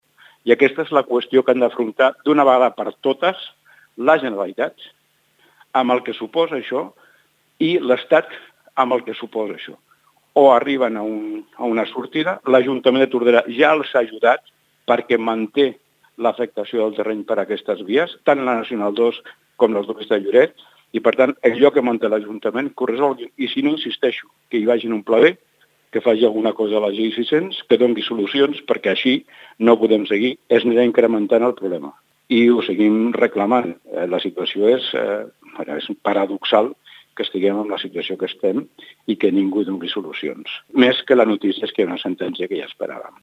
L’alcalde de Tordera, Joan Carles Garcia assegura que aquesta sentència estava anunciada, atesa les mancances que hi havia en el projecte fet per la Generalitat.